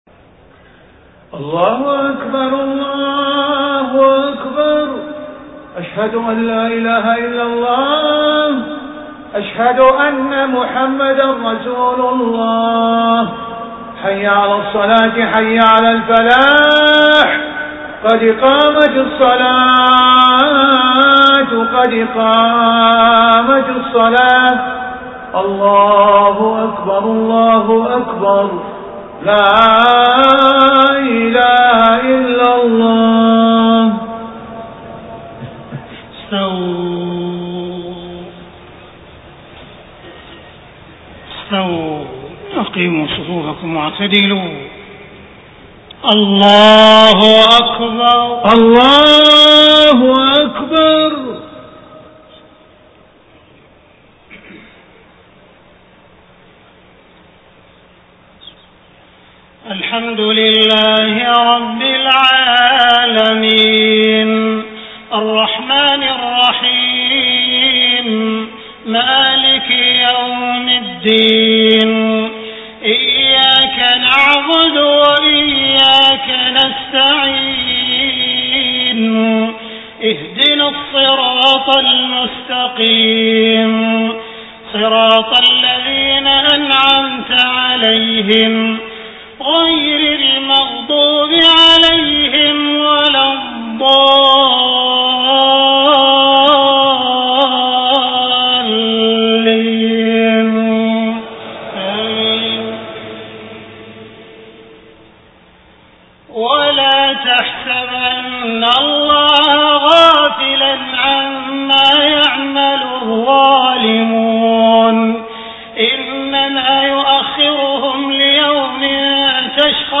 صلاة المغرب 25 صفر 1431هـ خواتيم سورة إبراهيم 42-52 > 1431 🕋 > الفروض - تلاوات الحرمين